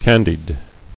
[can·died]